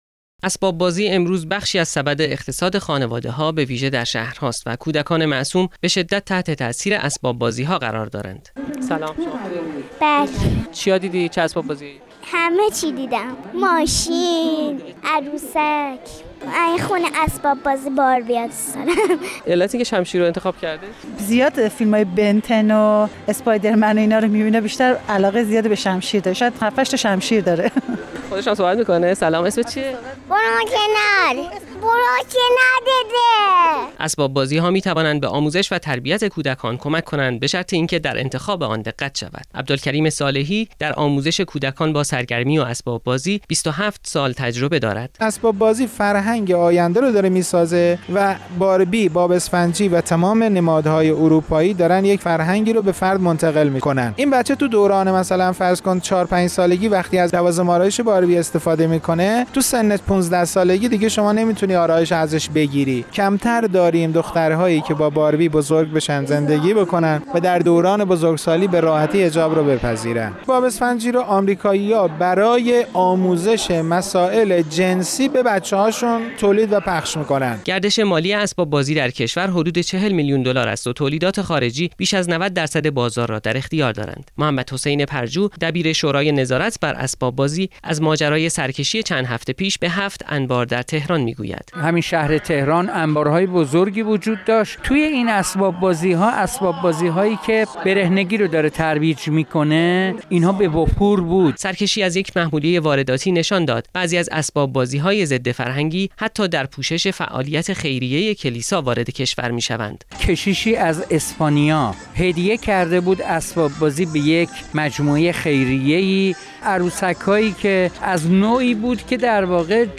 گزارش شنیدنی/ اسباب بازی‌های وارداتی با بچه‌های ما چه می‌کند؟